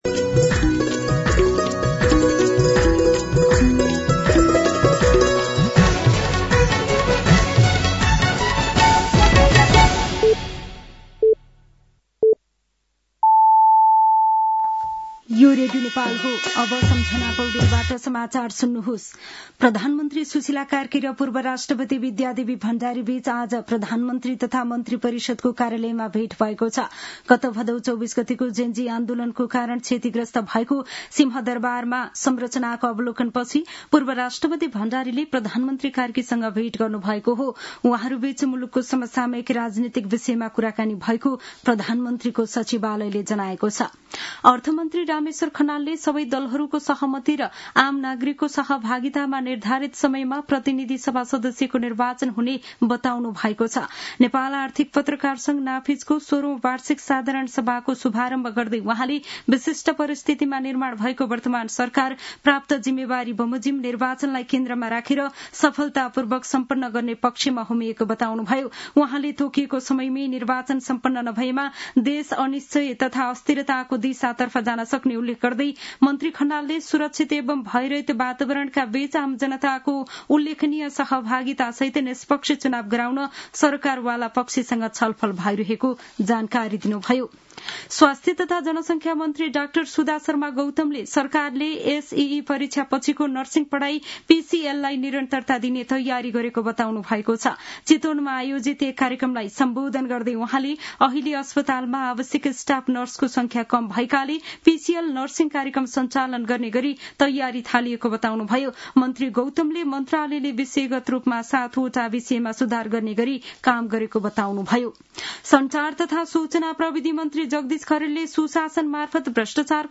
साँझ ५ बजेको नेपाली समाचार : २१ मंसिर , २०८२
5-PM-News-8-21.mp3